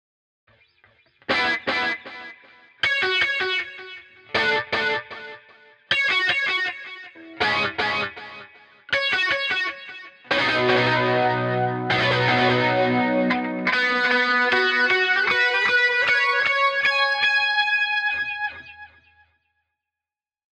• Con Delay: